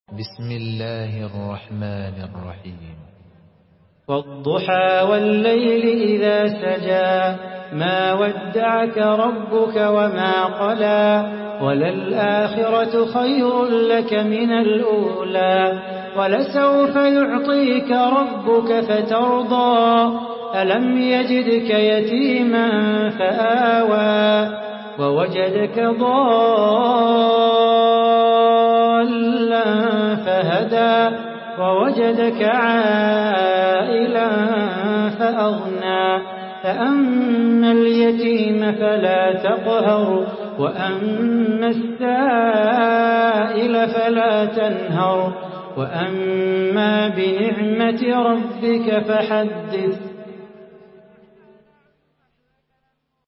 Surah Ad-Duhaa MP3 in the Voice of Salah Bukhatir in Hafs Narration
Murattal